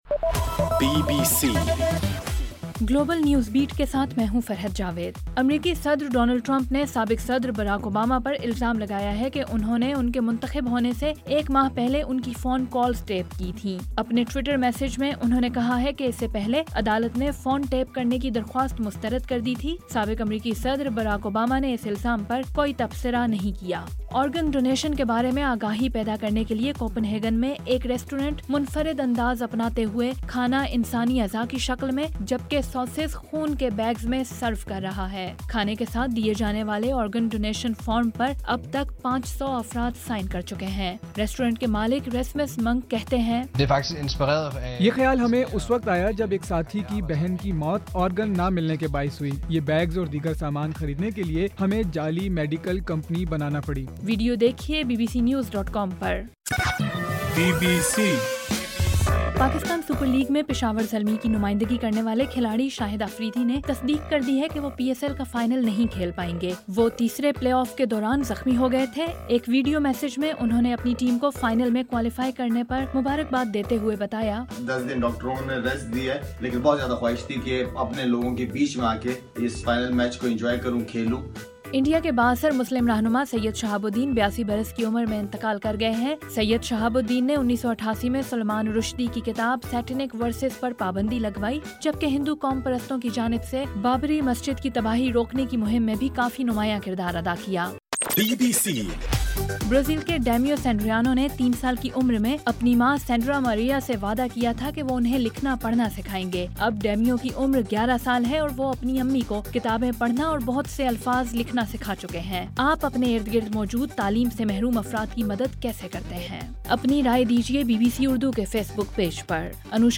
نیوز بُلیٹن